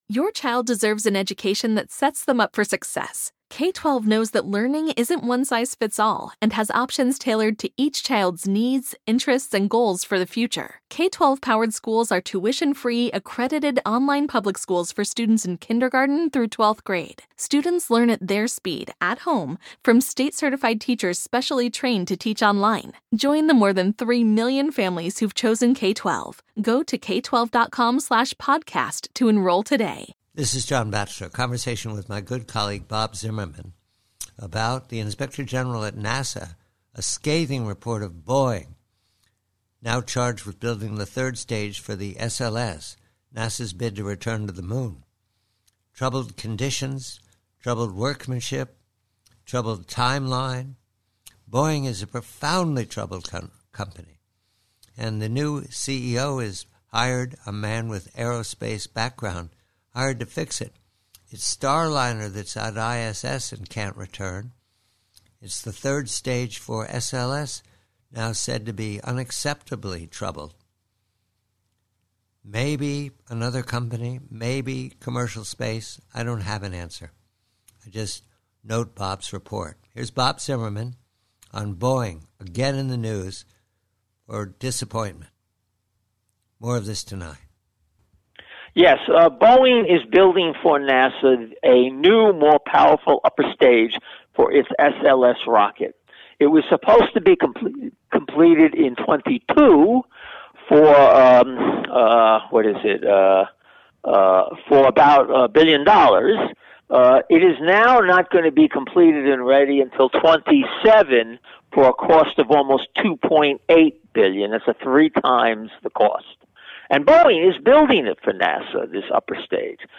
PREVIEW: BOEING: Conversation